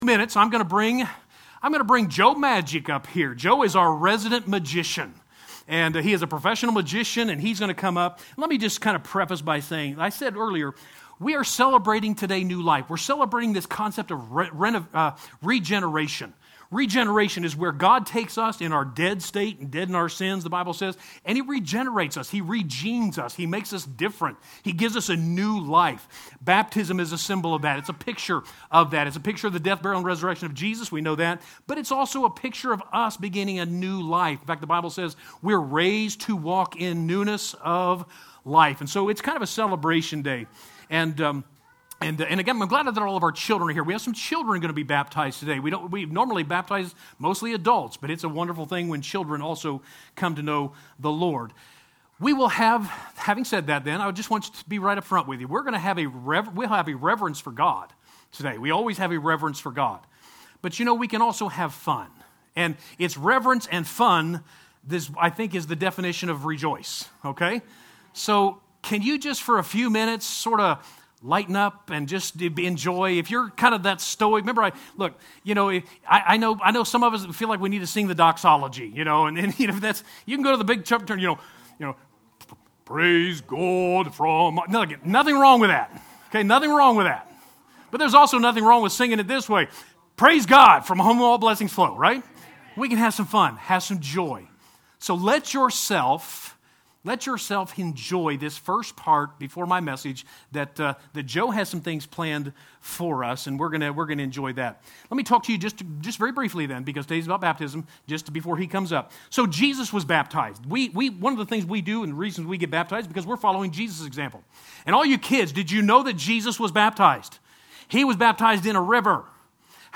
3-28-21 Sunday Message